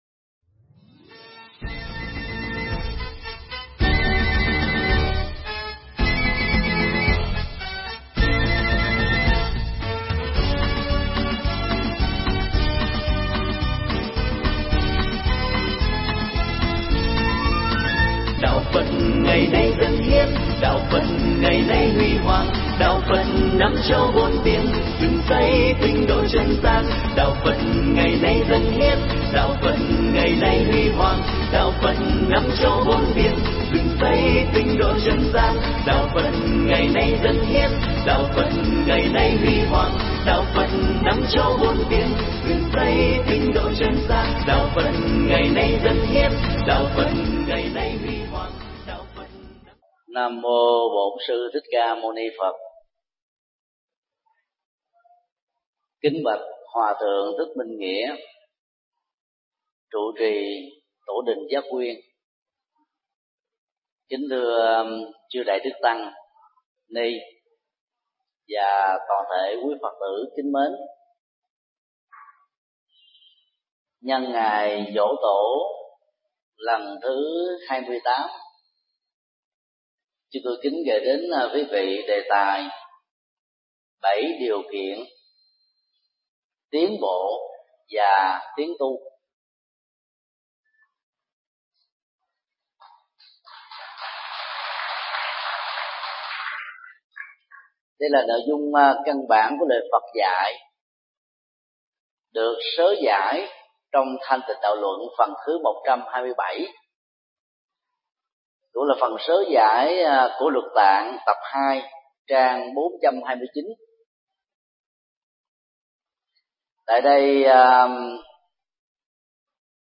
Mp3 Thuyết Pháp Bảy Điều Kiện Tiến Bộ và Tiến Tu – Do Thầy Thích Nhật Từ Giảng tại chùa Giác Nguyên, ngày 2 tháng 10 năm 2012